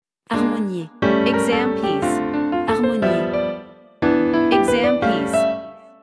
• 人声数拍
• 大师演奏范例